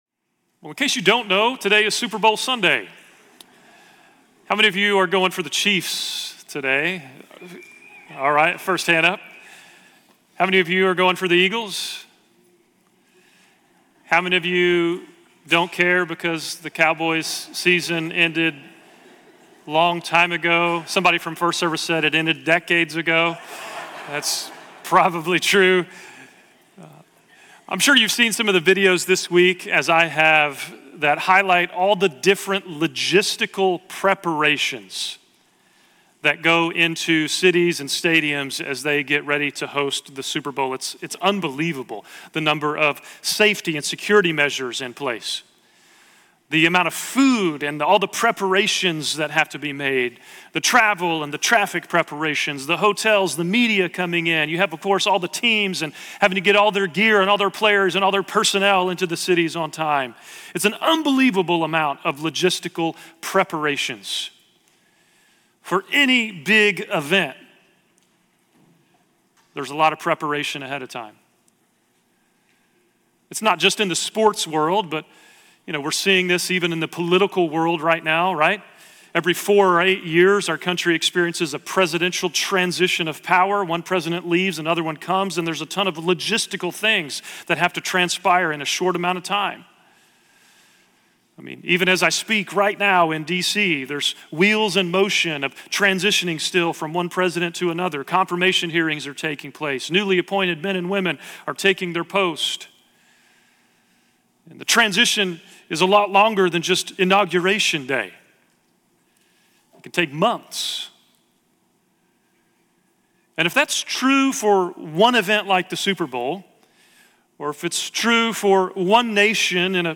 Grace Bible Church Dallas Sermons “Who Is Able To Stand?”